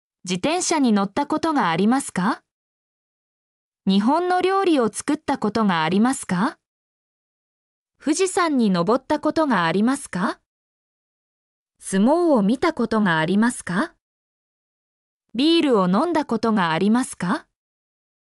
mp3-output-ttsfreedotcom-47_qexI0lAu.mp3